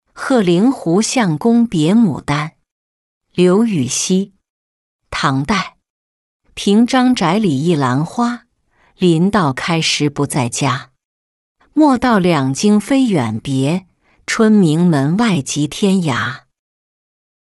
和令狐相公别牡丹-音频朗读